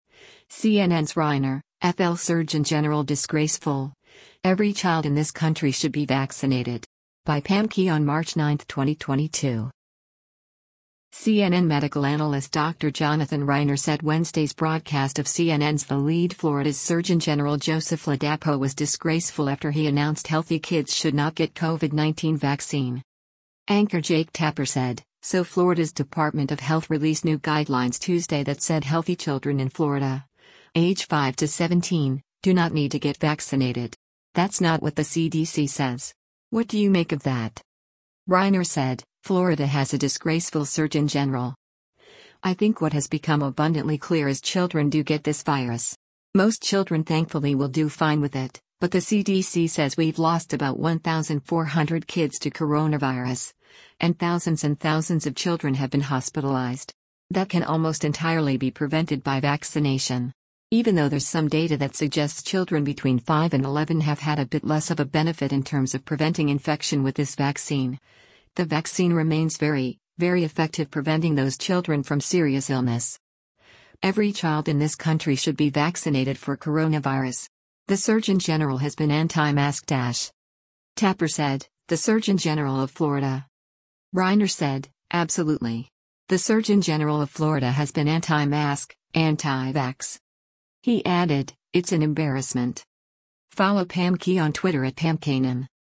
CNN medical analyst Dr. Jonathan Reiner said Wednesday’s broadcast of CNN’s “The Lead” Florida’s surgeon general Joseph Ladapo was “disgraceful” after he announced healthy kids should not get COVID-19 vaccine.